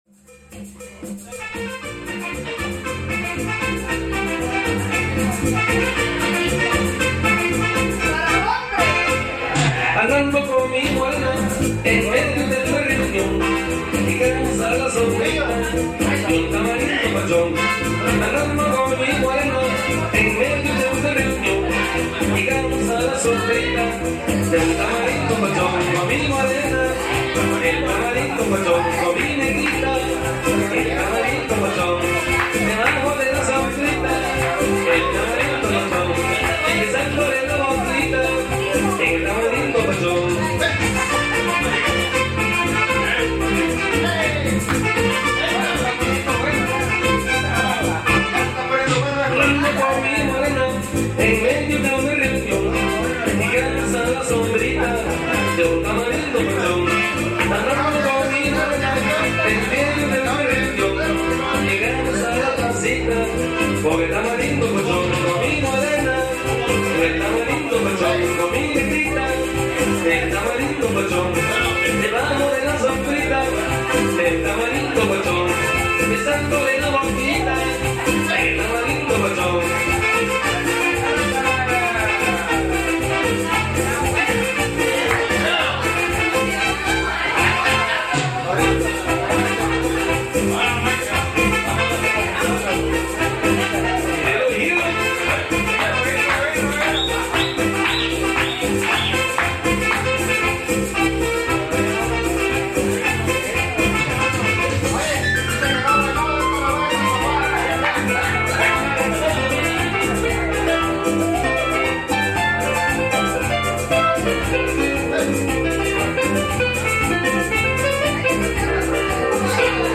cumbia
Disfrutemos de este paisaje sonoro cargado de fiesta y alegría.
Lugar: Azoyú, Guerrero; Mexico.
Equipo: Grabadora Sony ICD-UX80 Stereo Fecha: 2011-02-26 23:41:00 Regresar al índice principal | Acerca de Archivosonoro